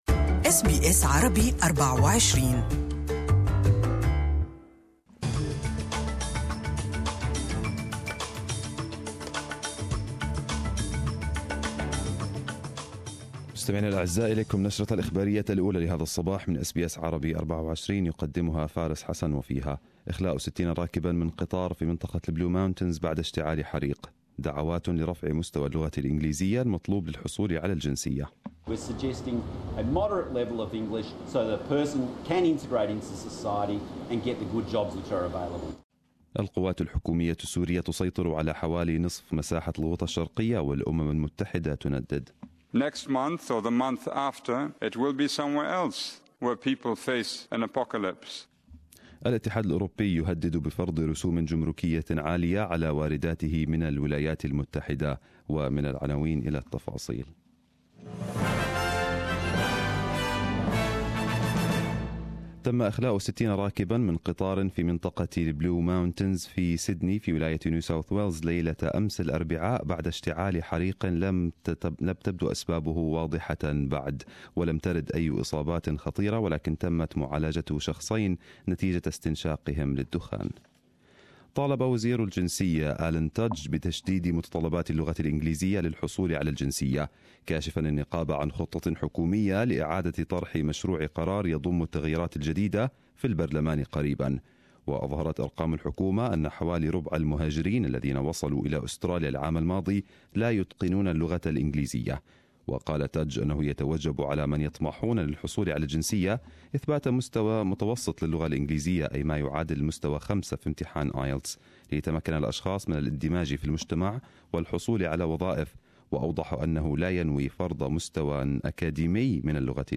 Arabic news bulletin 08/03/2018